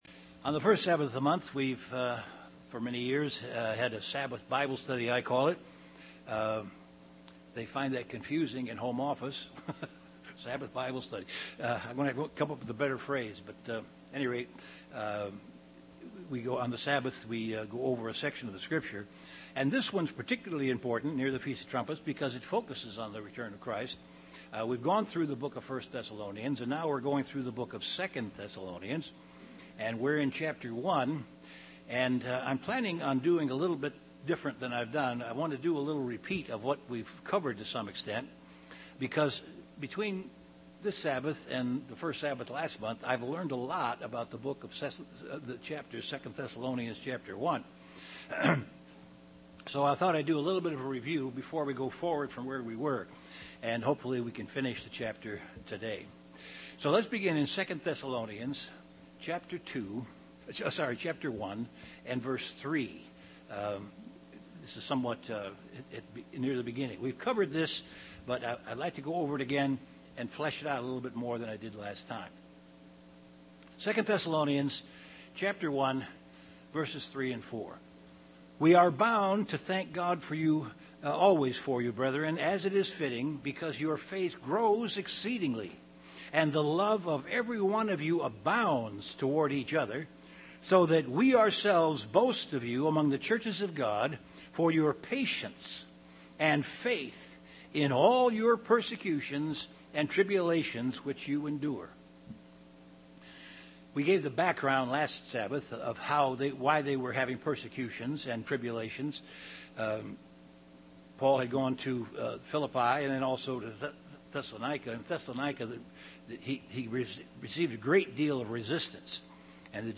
Given in Chicago, IL Beloit, WI
UCG Sermon Studying the bible?